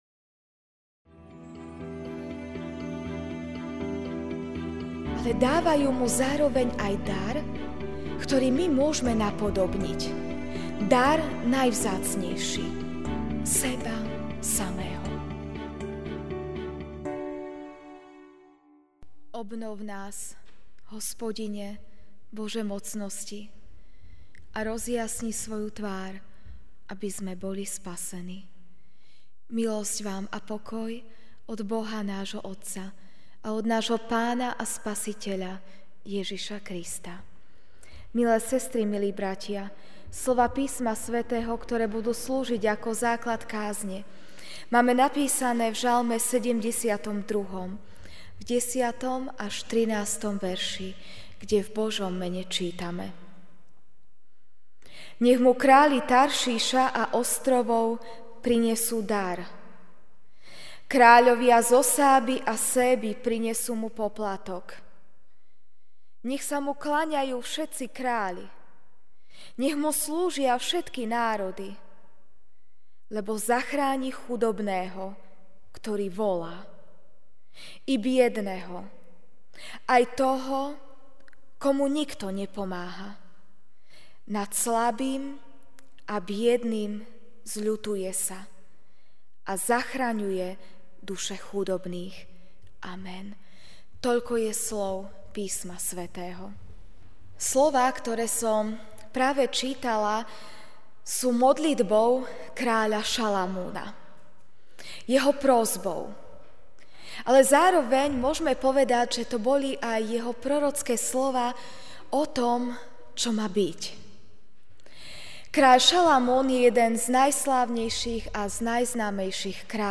Večerná kázeň: Kráľovské dary (Ž 72,10-13) Nech mu králi Taršíša a ostrovov prinesú dar, kráľovia zo Sáby a Seby prinesú mu poplatok.